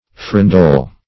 Farandole \Fa`ran`dole"\, n. [F. farandole, Pr. farandoulo.]